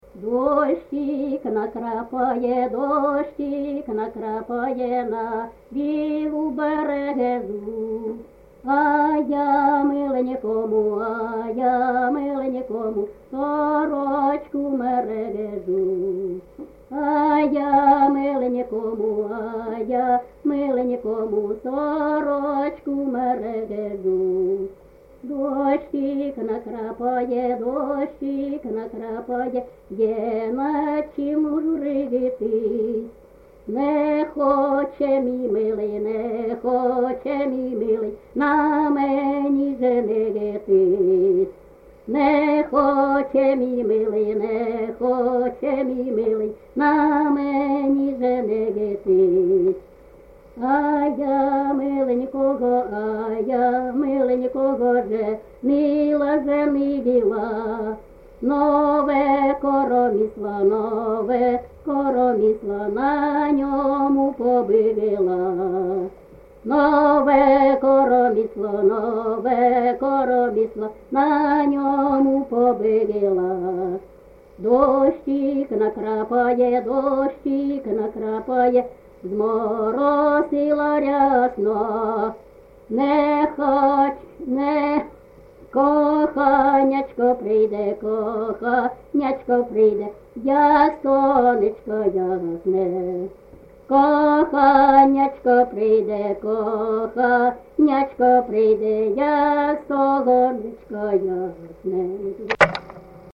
ЖанрПісні з особистого та родинного життя, Жартівливі
Місце записус-ще Михайлівське, Сумський район, Сумська обл., Україна, Слобожанщина